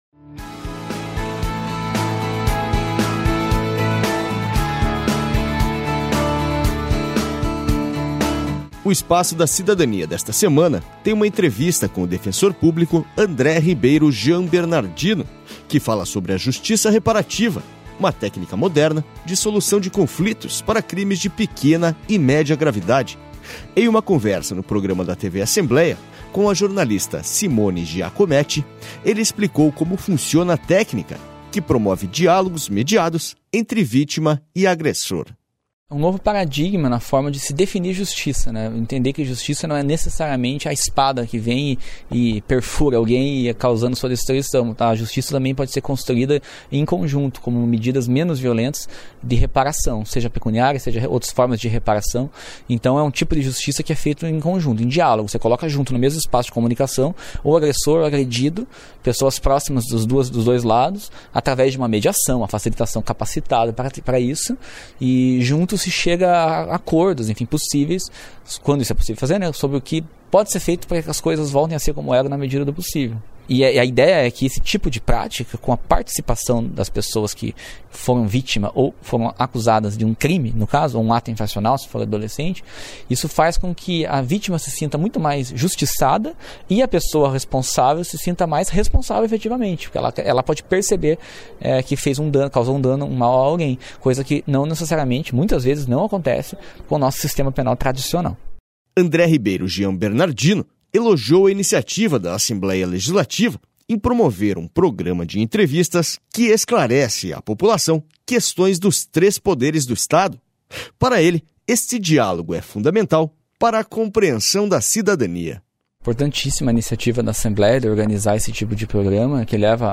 O Espaço da Cidadania desta semana tem uma entrevista